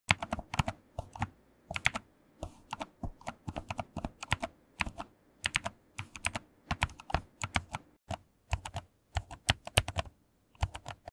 Typing Never Felt This Soft Sound Effects Free Download